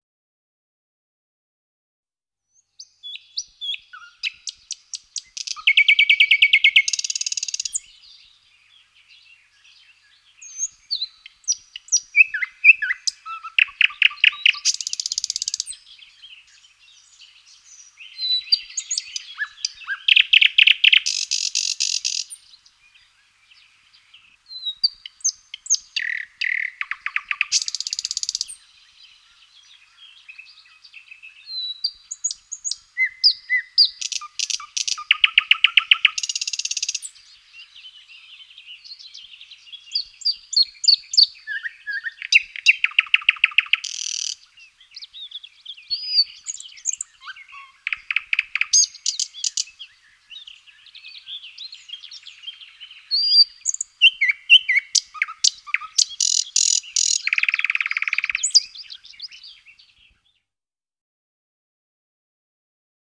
Песня соловья:
Соловей.mp3